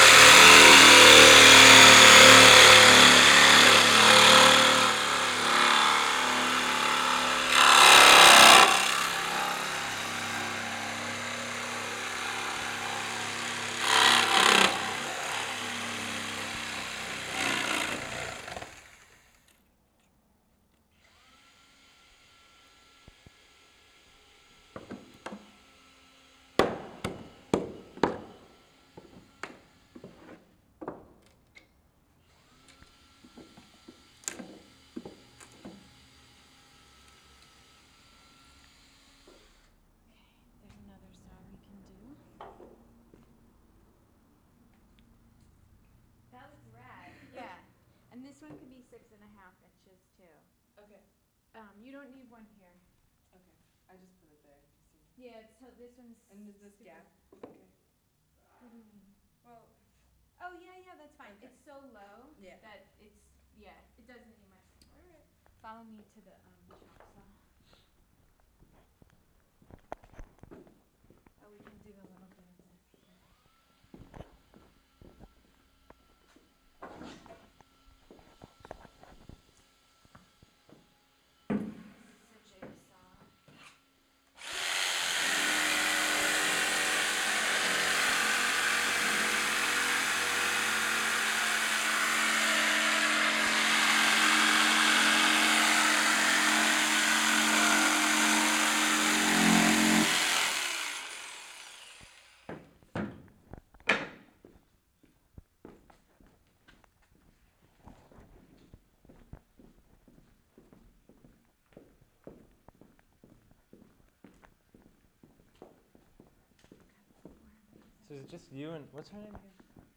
am interview
. we made some concrete music today, with a sawzall, chopsaw, jigsaw, and drill gun. a taste tester of our conversation can be found